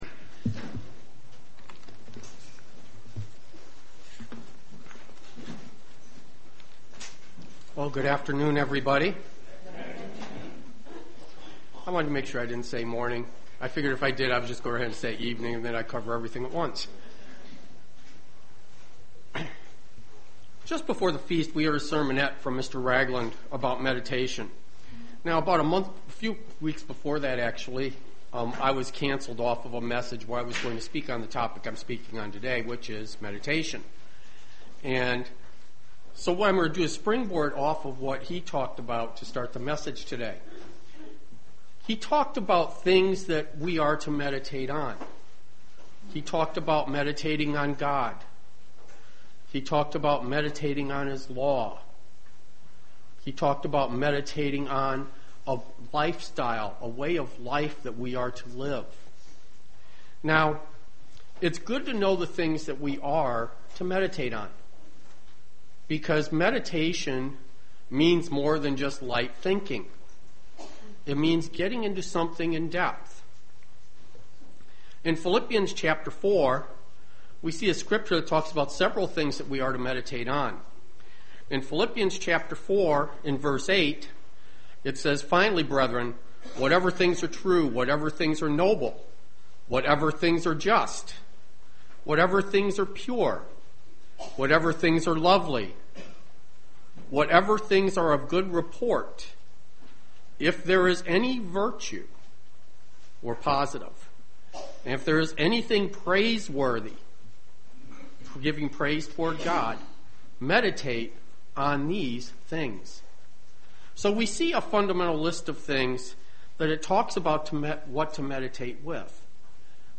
UCG Sermon Studying the bible?
Given in Dayton, OH